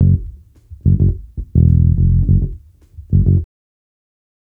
Bass Lick 34-06.wav